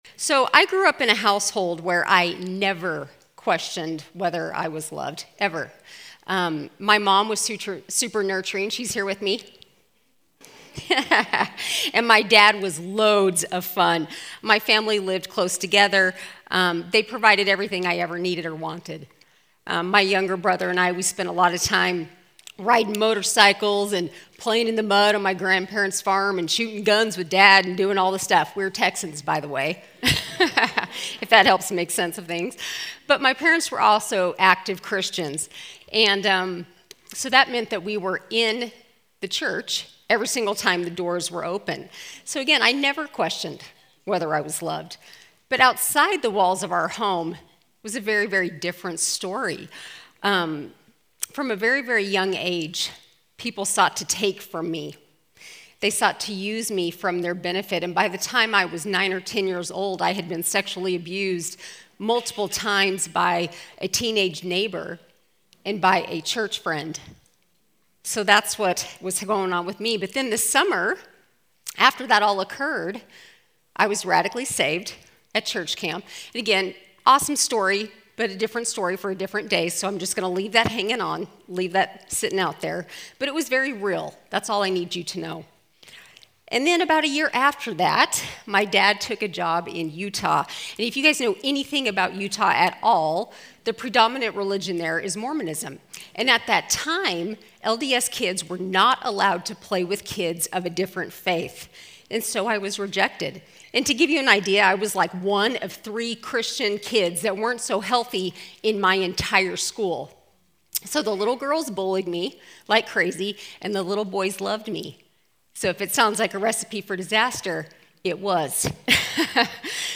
2024 DSWG Conference: Worship Conference Date